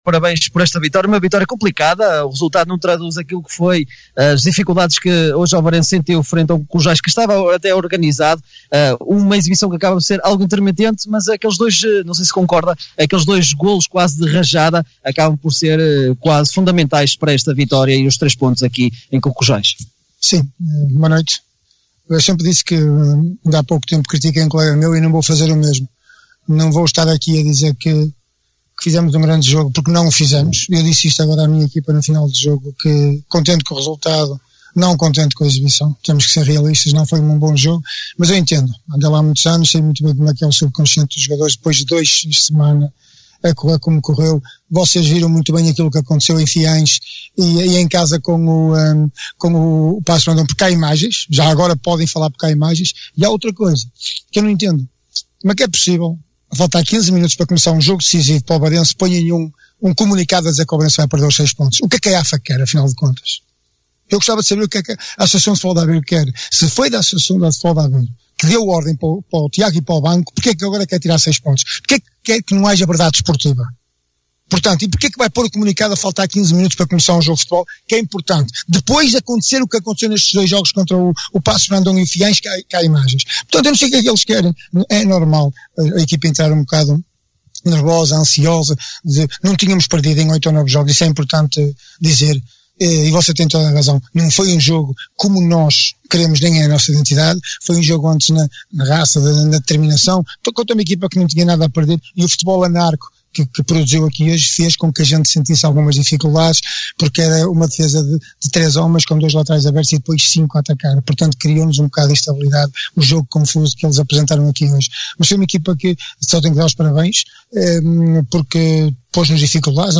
Convidamo-lo a ouvir as declarações dos técnicos no fecho da partida que opôs Cucujães e Ovarense.